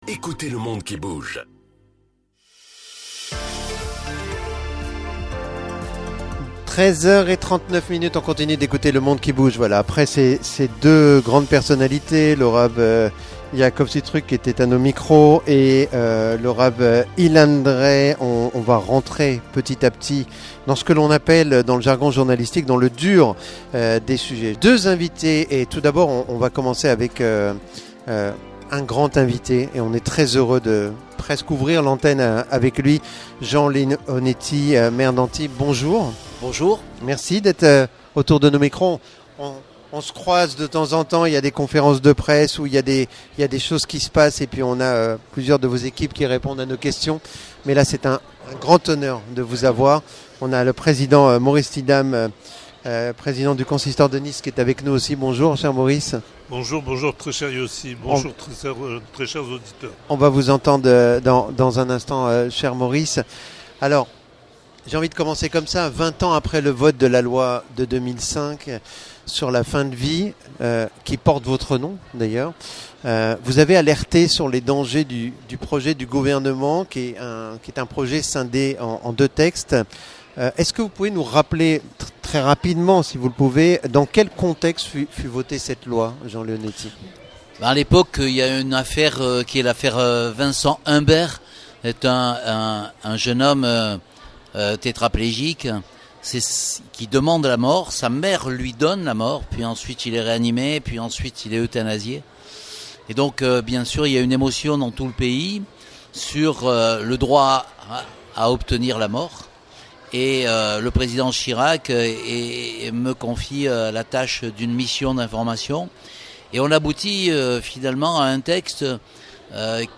Jean LEONETTI Maire d'Antibes depuis Yom Hatorah au Palais des Congrès d'Antibes - RCN - Ecoutez le monde qui bouge Interviews
Jean LEONETTI Maire d'Antibes depuis Yom Hatorah au Palais des Congrès d'Antibes